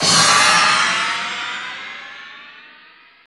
SI2 WINDCH03.wav